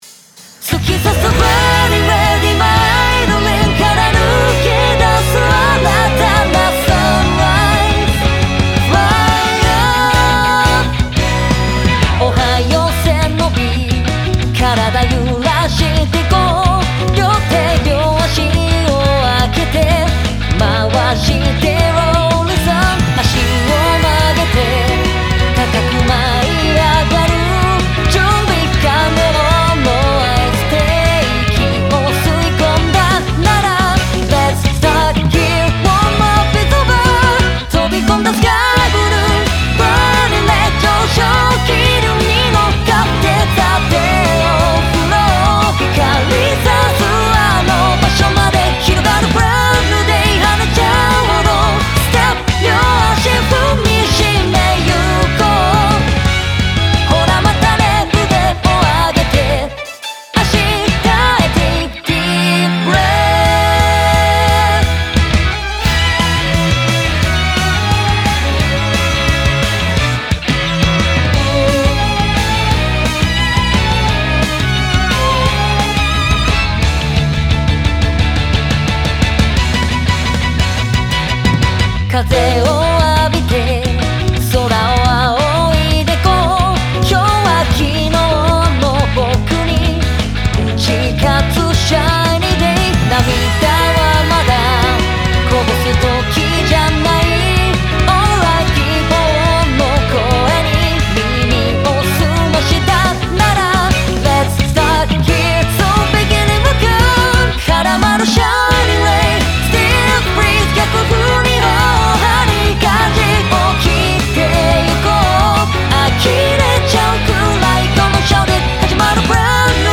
超爽快な朝を迎えられそうな爽やかなボーカル入りBGM
ポップ, ロック 3:39 ダウンロード